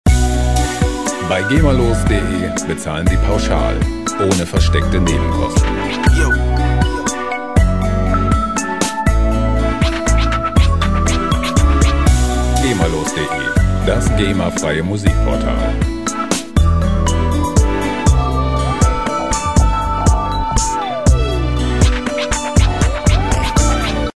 gema-freie Hip-Hop Loops
Musikstil: Trip-Hop
Tempo: 120 bpm